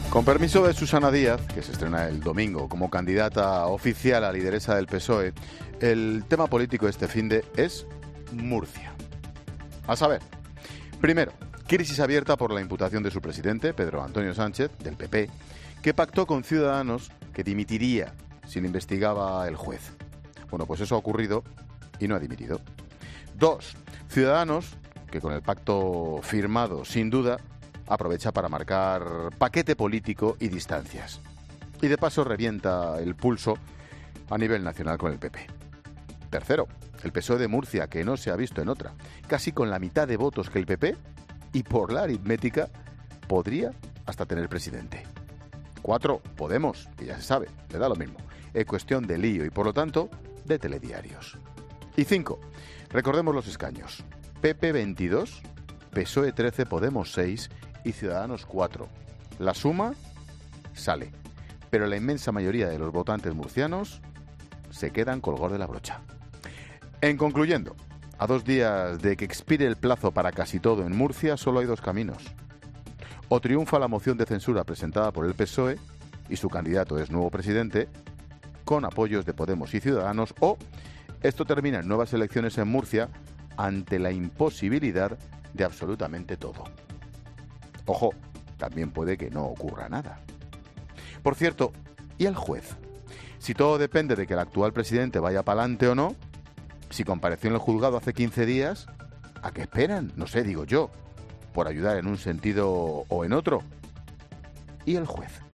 AUDIO: Monólogo 17h.